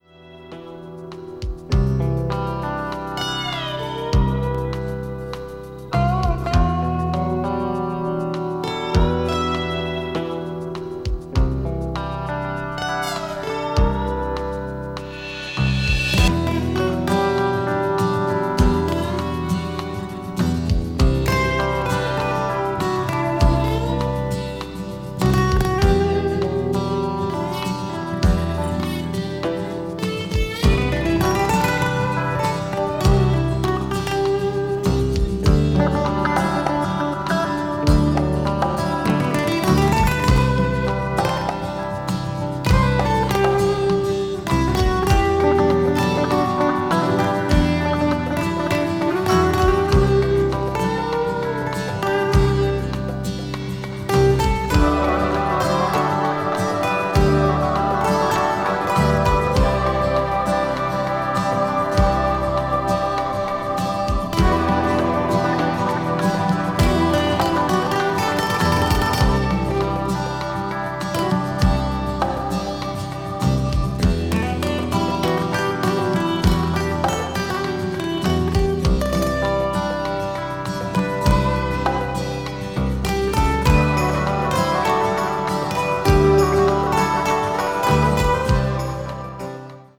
acoustic   blues   country   folk   new age   synthesizer